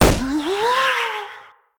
balloon_ghost_pop_03.ogg